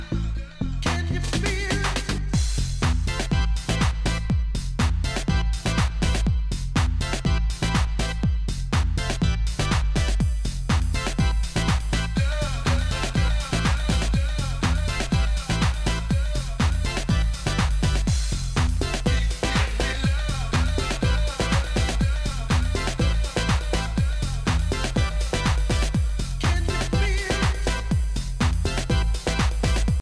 Authentic USG swing and organ glide.